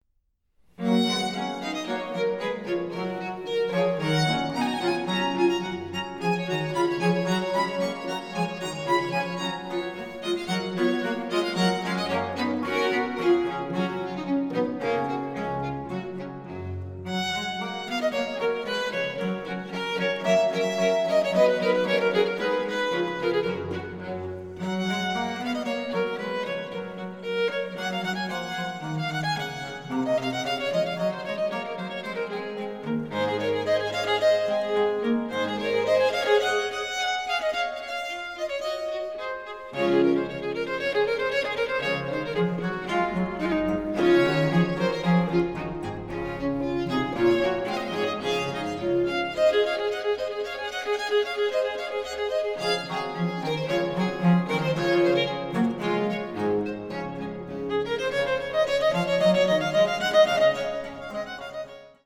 Ausschnitt-Sonata-G-Dur.mp3